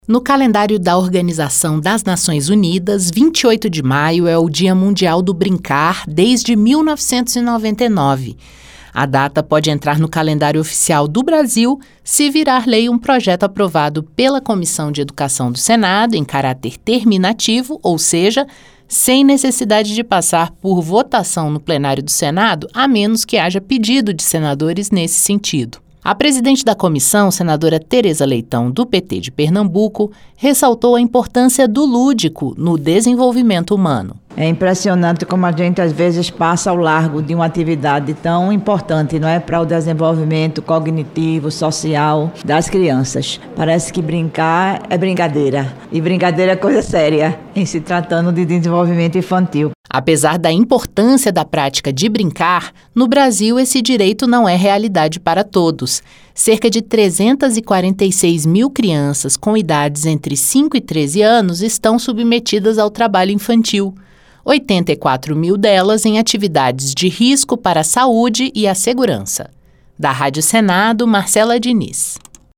A presidente da Comissão de Educação, senadora Teresa Leitão (PT-PE), ressaltou a importância do elemento lúdico no desenvolvimento humano.